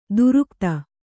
♪ durukta